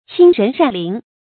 親仁善鄰 注音： ㄑㄧㄣ ㄖㄣˊ ㄕㄢˋ ㄌㄧㄣˊ 讀音讀法： 意思解釋： 見「親仁善鄰」。